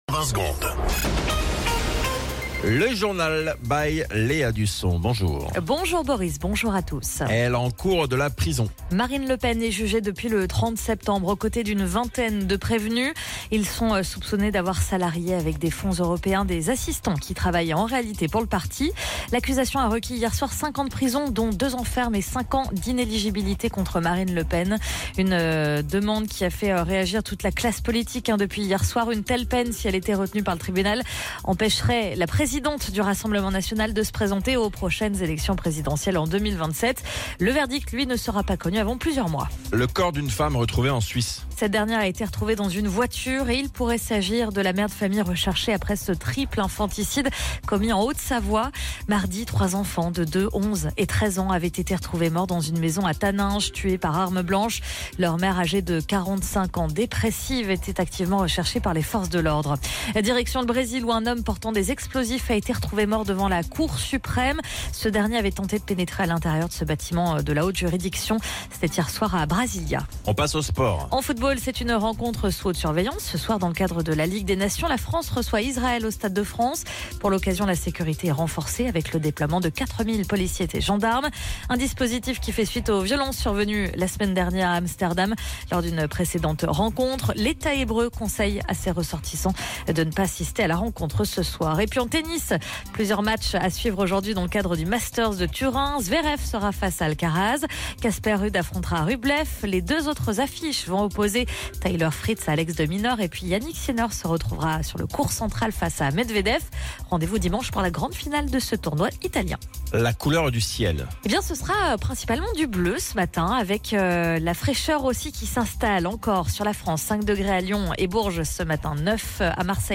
Flash Info National 14 Novembre 2024 Du 14/11/2024 à 07h10 .